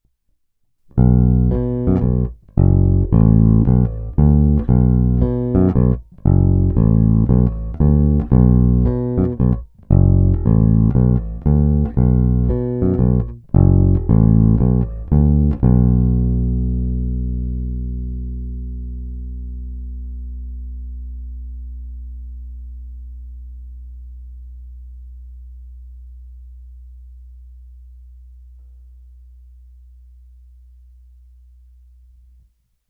Má hodně výrazné středy, je poměrně agresívní, kousavý.
Není-li uvedeno jinak, následující ukázky byly provedeny rovnou do zvukové karty a s plně otevřenou tónovou clonou, jen normalizovány, jinak ponechány bez úprav.
Hra mezi snímačem a kobylkou